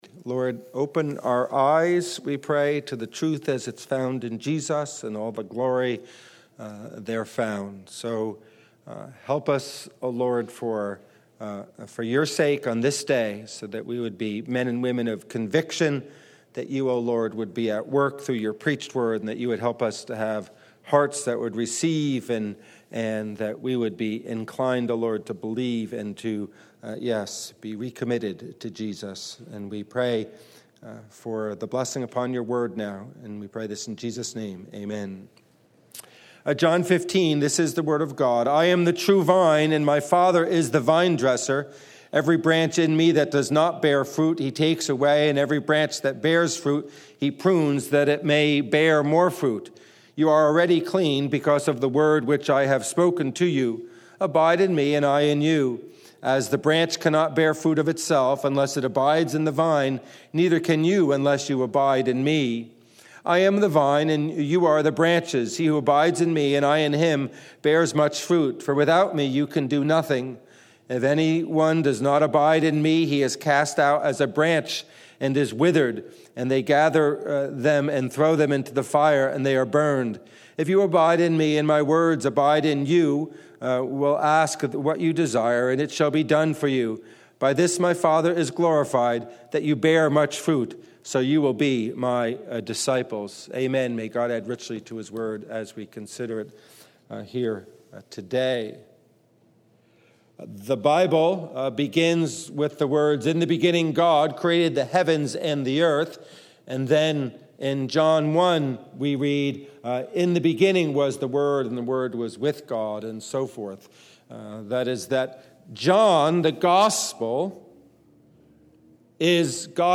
Passage: John 15:1-7 Service Type: Morning Worship